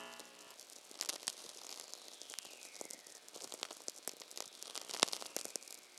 Archived Whistler Event Data for 2024-05-15 Forest, VA USA